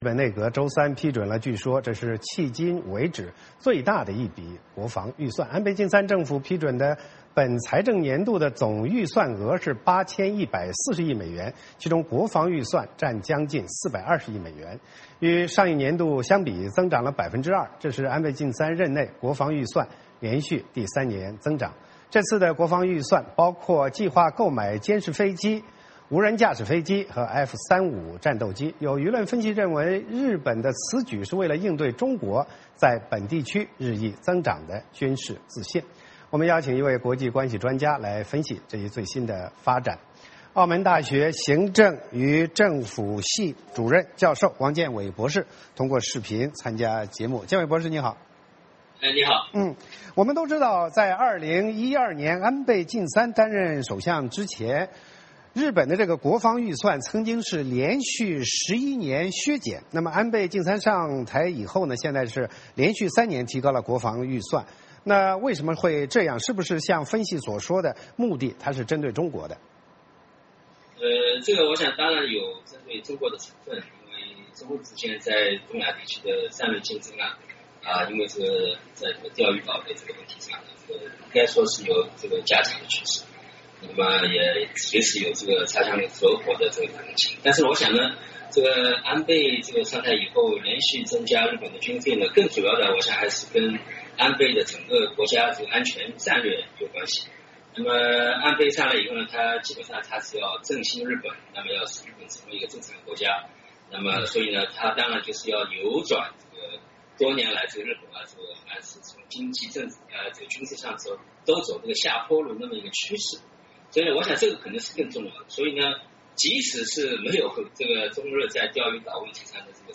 有舆论分析认为，日本此举是为了应对中国在本地区日益增长的军事自信。我们邀请了国际关系专家